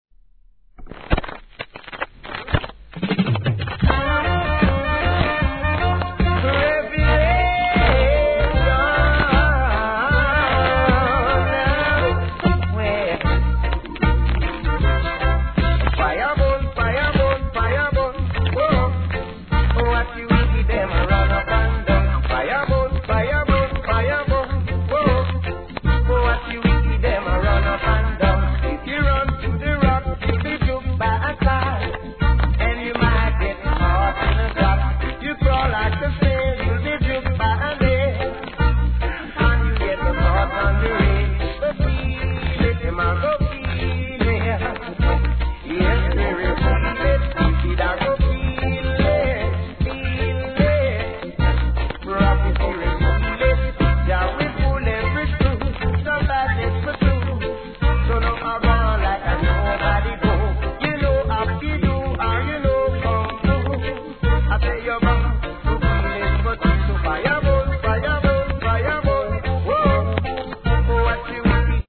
頭に深い傷あり、最初の５周程度大きなプツあります
REGGAE